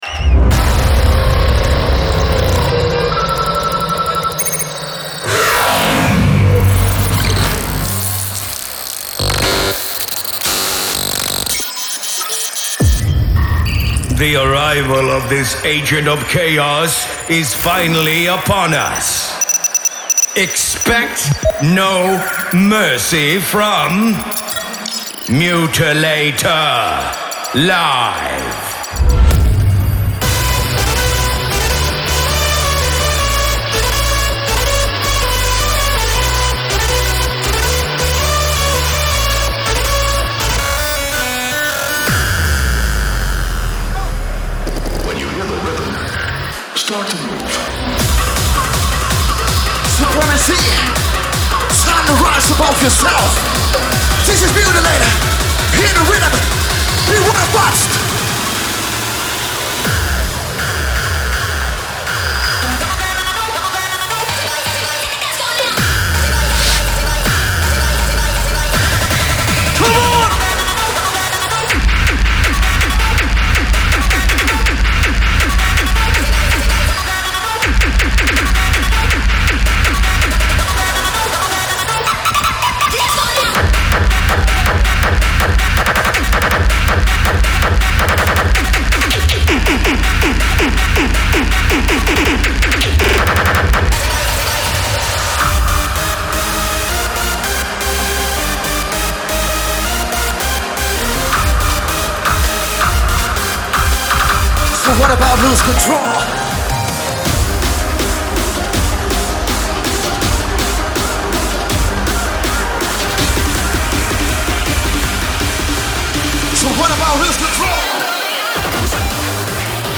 Also find other EDM Livesets,